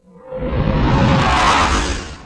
Slam2-3.wav